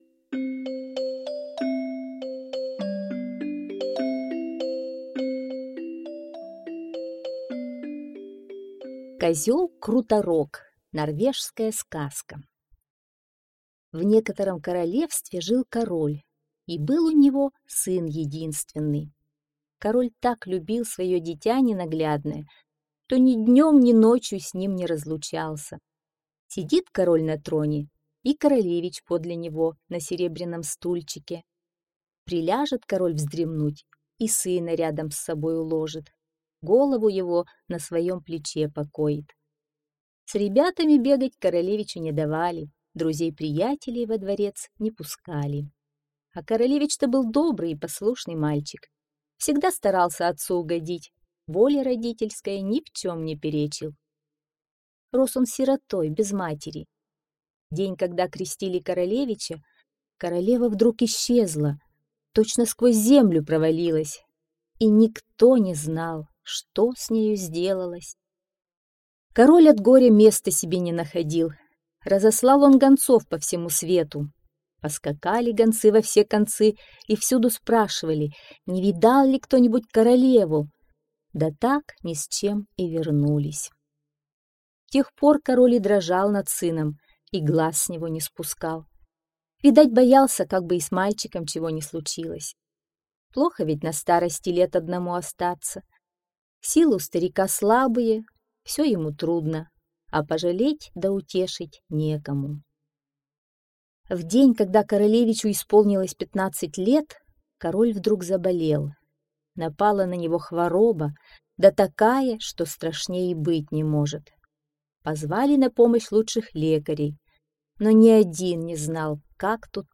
Козёл-Круторог - норвежская аудиосказка.